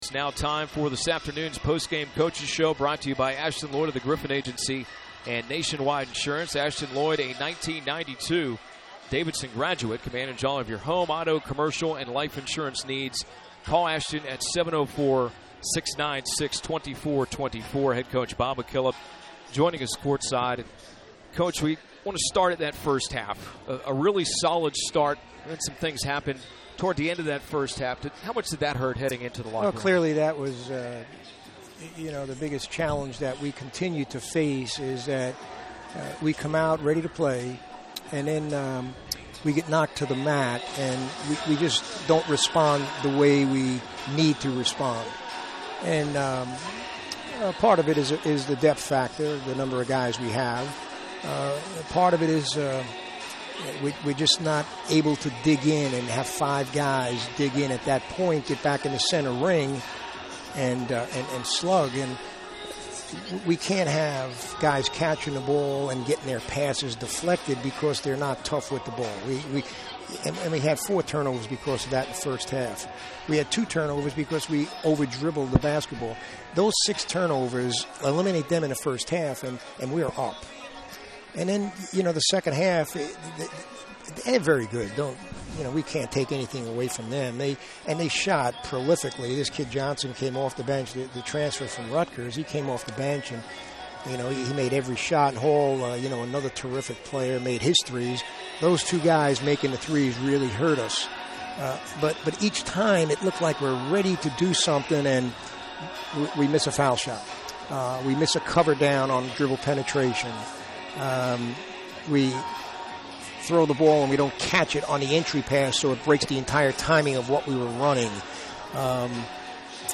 Postgame Interview
Postgame UVA.mp3